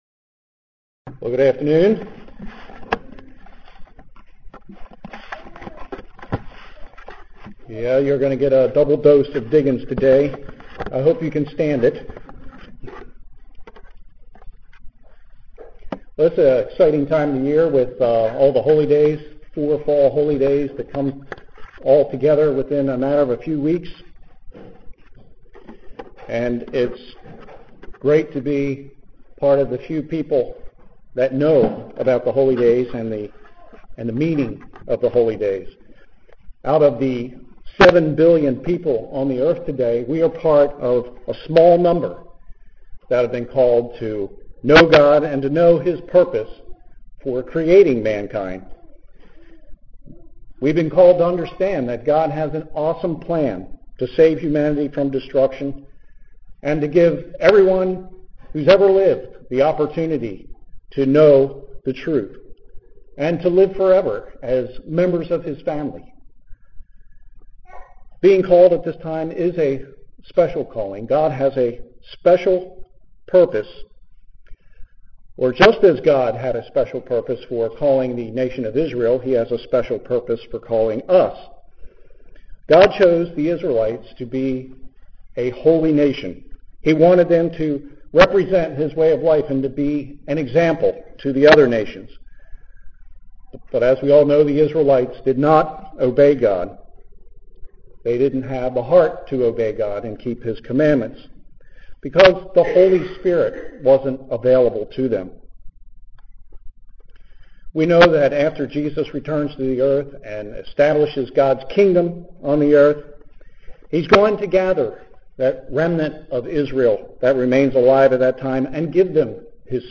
Sermons
Given in Columbia, MD